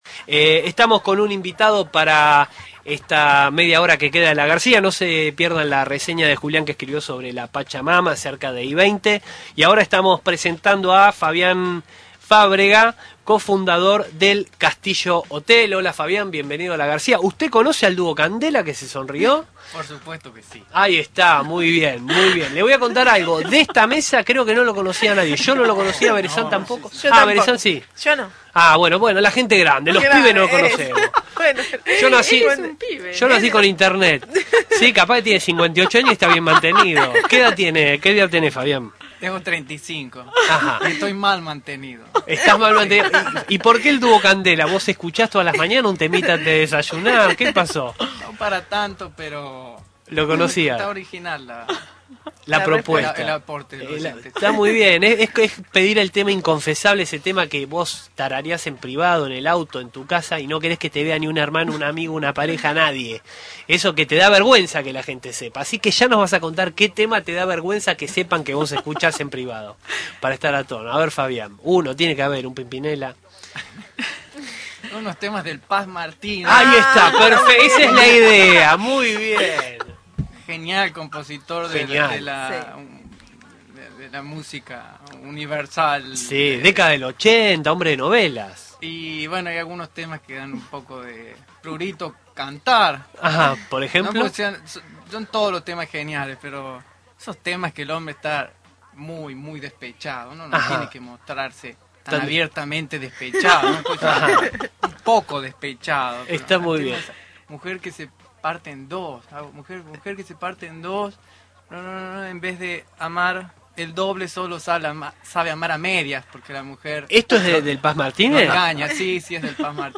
nota-radio-cooperativa.mp3